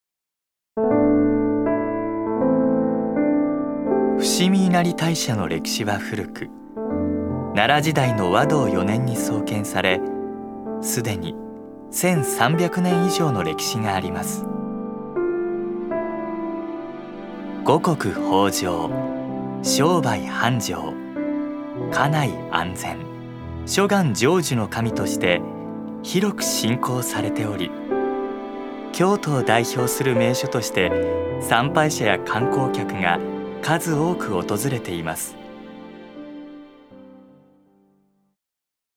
所属：男性タレント
ナレーション１